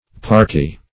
Parka \Par"ka\, Parkee \Par"kee\, n. [Russ. parka, parki, dim.